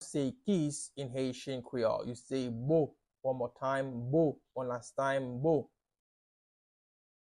Listen to and watch “Bo” pronunciation in Haitian Creole by a native Haitian  in the video below:
How-to-say-Kiss-in-Haitian-Creole-Bo-pronunciation-by-a-Haitian-Creole-teacher.mp3